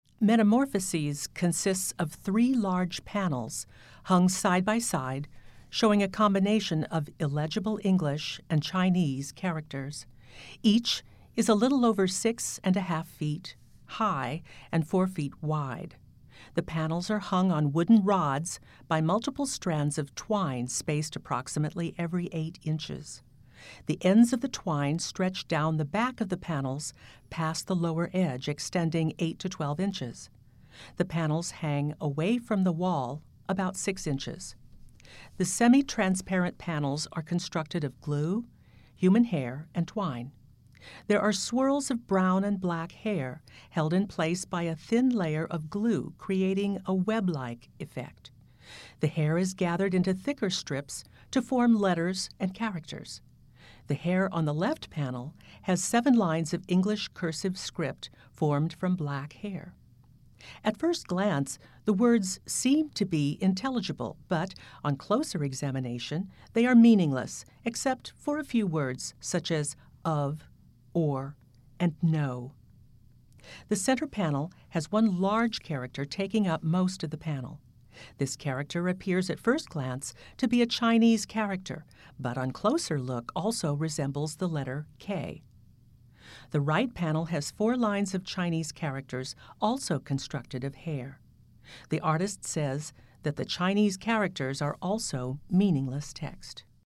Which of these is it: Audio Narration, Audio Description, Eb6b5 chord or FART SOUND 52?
Audio Description